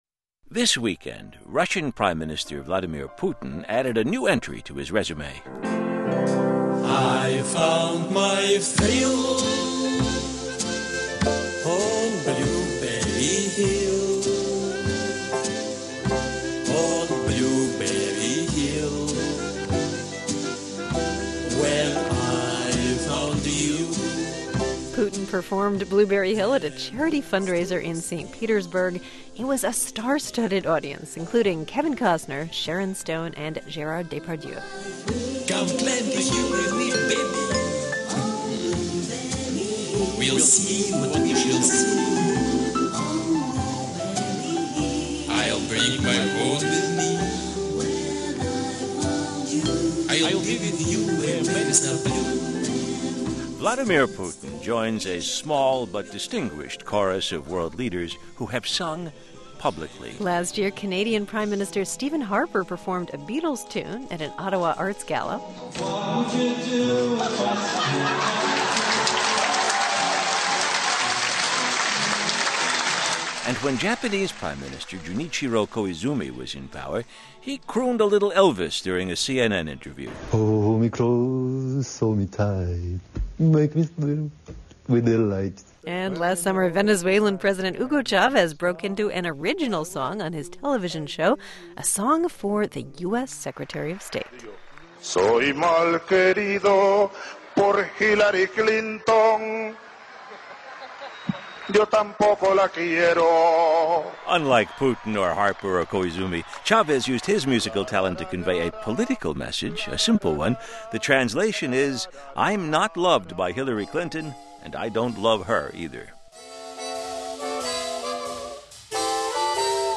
Russian Prime Minister Vladimir Putin displayed his musical talents, performing a piano solo and singing "Blueberry Hill" for a cancer charity fundraiser at the ice stadium in St. Petersburg. As hosts Melissa Block and Robert Siegel tell us, he is not the first world leader to display vocal talent.